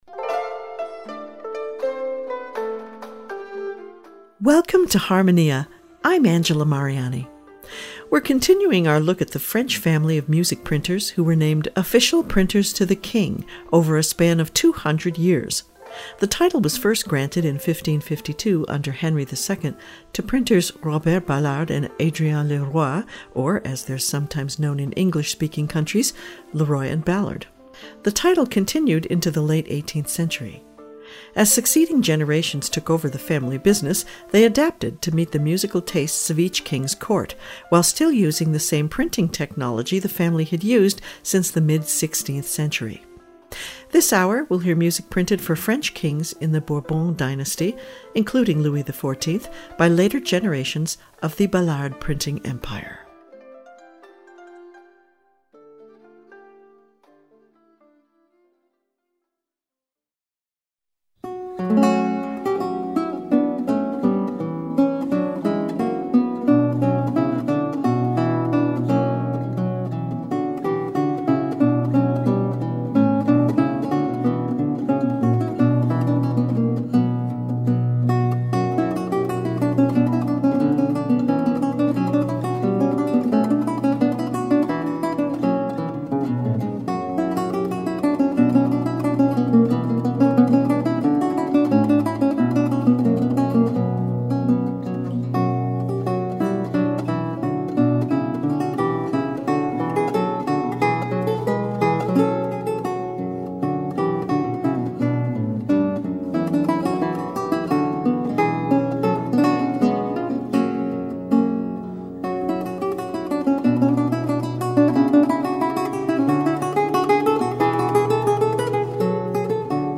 This hour, we continue our look at the French family of music publishers who were named printers to the king…over a span of 200 years! We’ll hear music published by later generations of the Ballard printing empire around the time of Louis XIV.